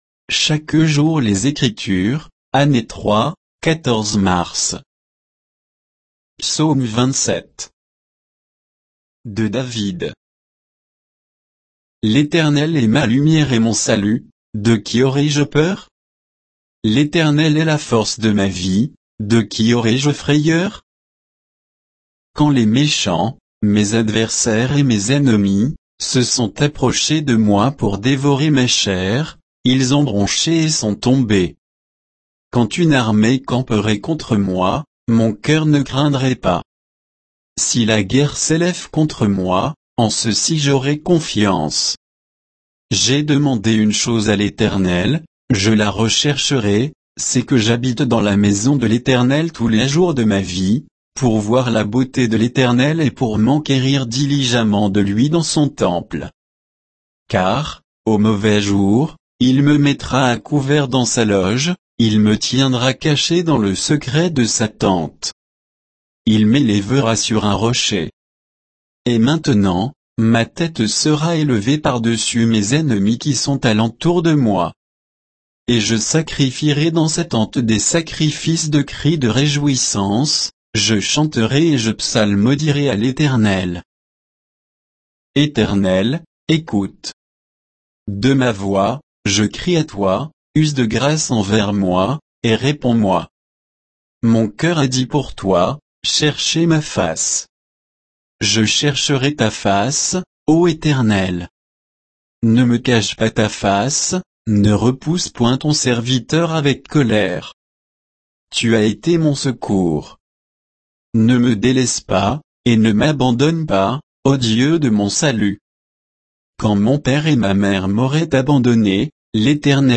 Méditation quoditienne de Chaque jour les Écritures sur Psaume 27